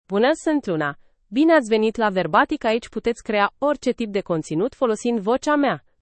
FemaleRomanian (Romania)
LunaFemale Romanian AI voice
Voice sample
Listen to Luna's female Romanian voice.
Female
Luna delivers clear pronunciation with authentic Romania Romanian intonation, making your content sound professionally produced.